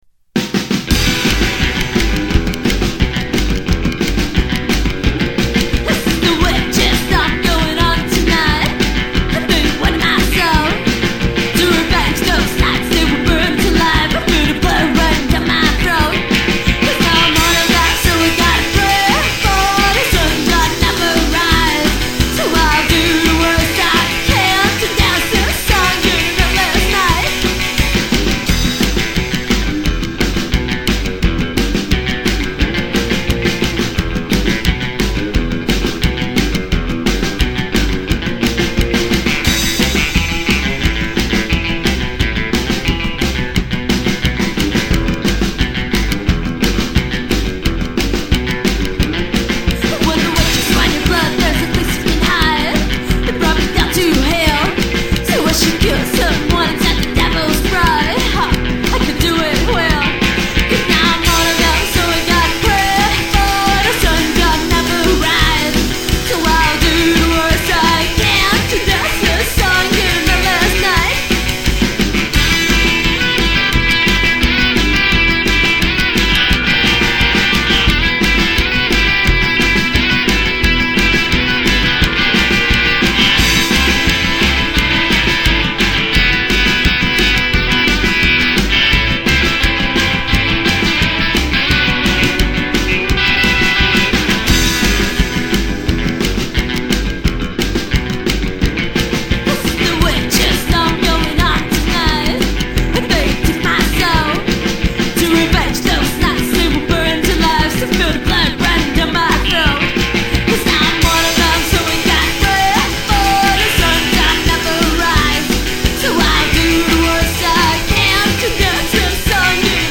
обзор дискографии девичьей сайкобилли группы из Бразилии
девчачье сайко-трио
буквально кичащийся своим примитивизмом.